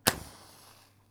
single match lighting, burning and blowing out.wav
Recorded indoor in the kitchen with a Tascam DR 40 linear PCM recorder, lighting up a single match to start the cooking machine.
single_match_lighting,_burning_and_blowing_out_2hK.wav